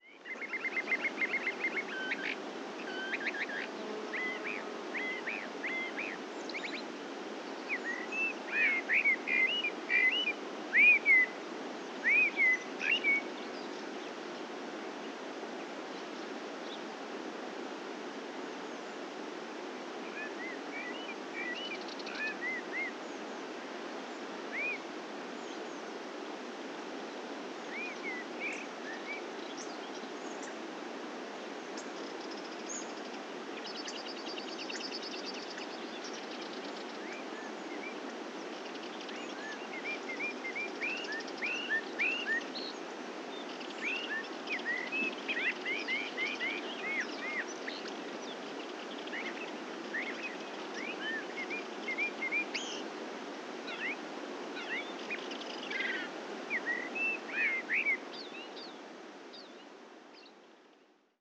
Ambiente de bosque mediterráneo 1
pájaro
Sonidos: Animales
Sonidos: Rural